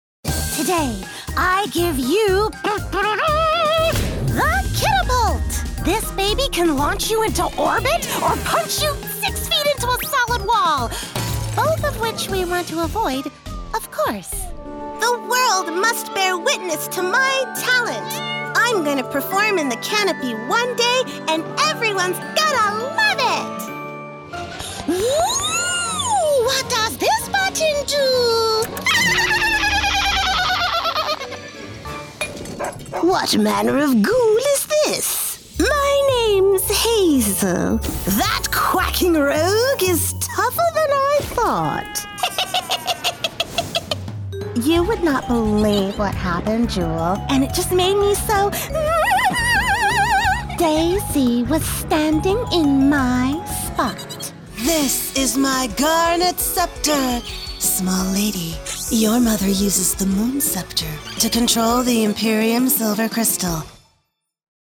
Kids Character Demo
FRENCH, RP, COCKNEY, US REGIONAL